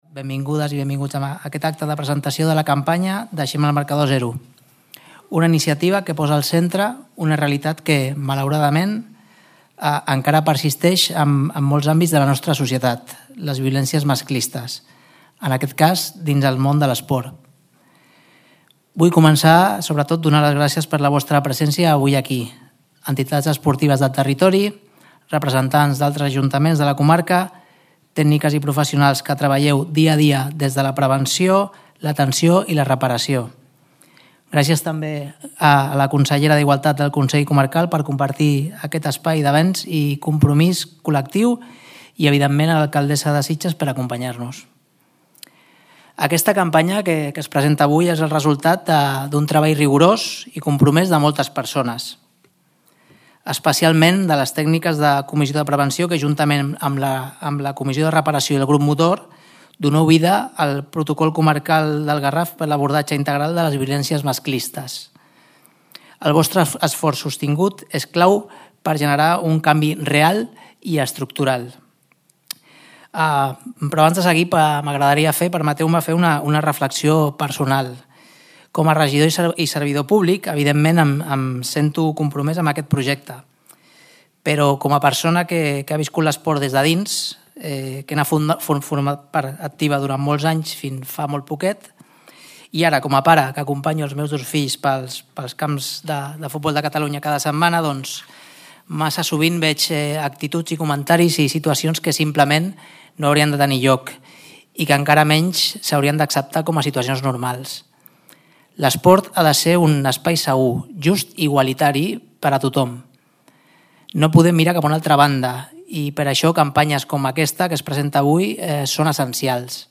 De cara a finals d’any, al desembre, es preveu cloure la campanya i fer-ne l’anàlisi de l’impacte al territori. Ha presentat l’acte el regidor de drets socials de Sitges Xavi Ripoll i la consellera delegada d’igualtat del consell comarcal, Rosalia Màrquez i ha clos la presentació l’alcaldessa Aurora Carbonell.